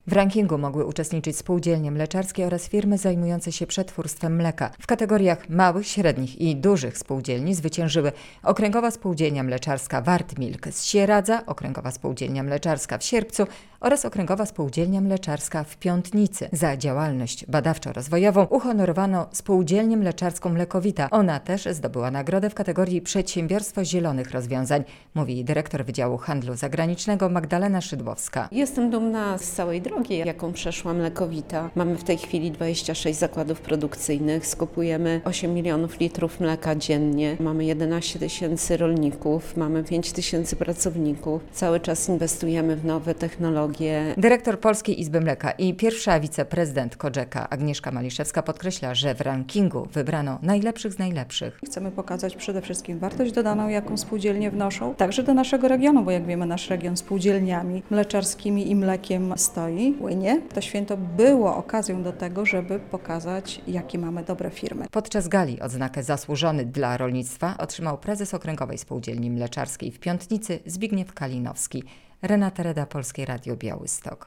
Ranking firm mleczarskich - relacja